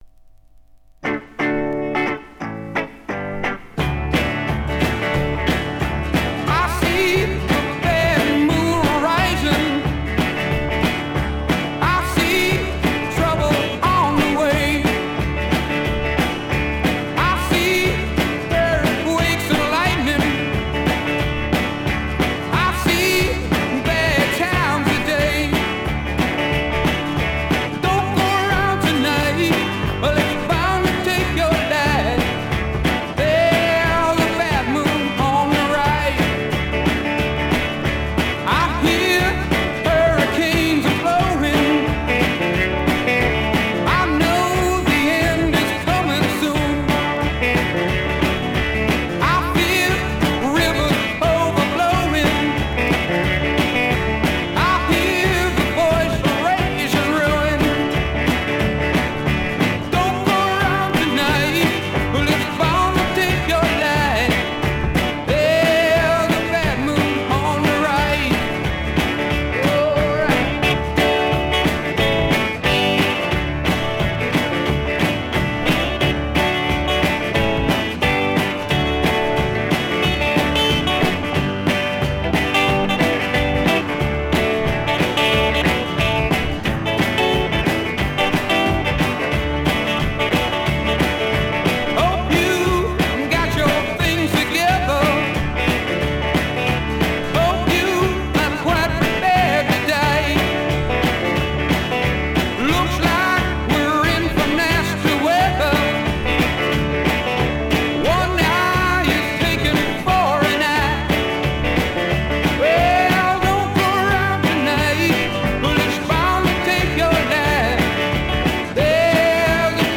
Жанр: Rock
Стиль: Classic Rock, Country Rock